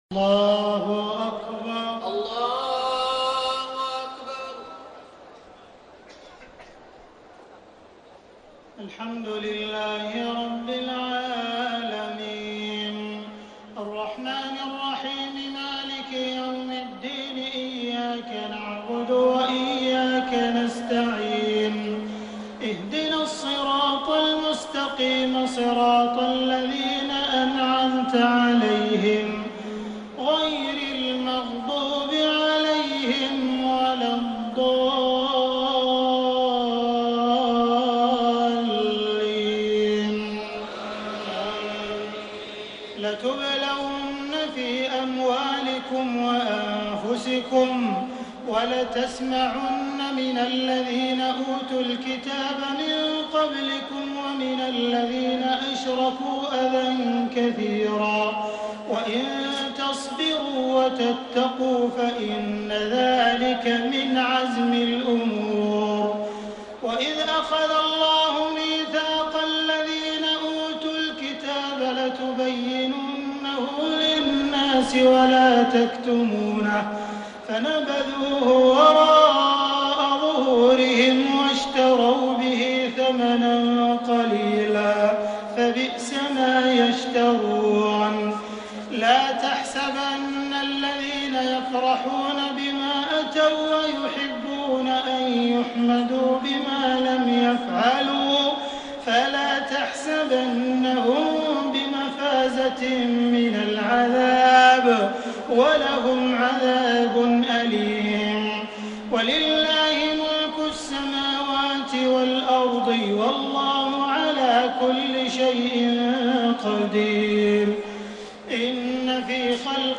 تهجد ليلة 24 رمضان 1433هـ من سورتي آل عمران (186-200) و النساء (1-24) Tahajjud 24 st night Ramadan 1433H from Surah Aal-i-Imraan and An-Nisaa > تراويح الحرم المكي عام 1433 🕋 > التراويح - تلاوات الحرمين